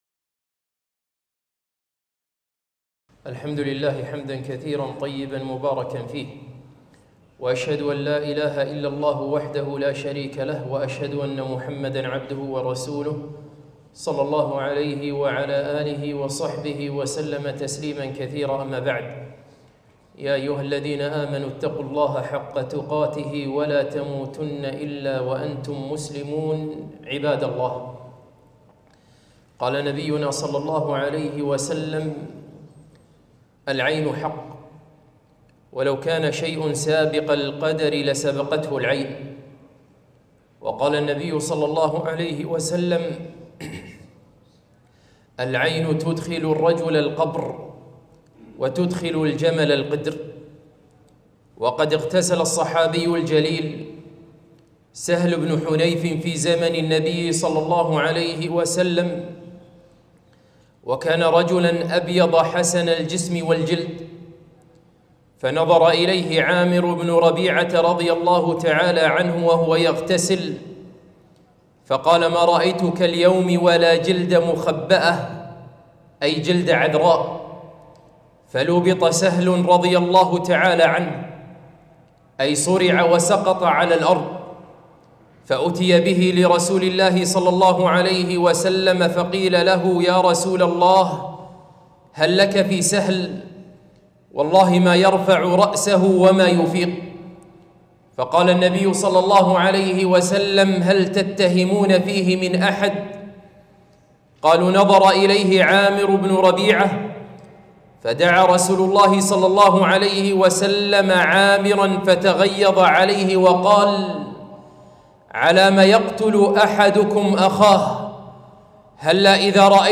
خطبة - العين حق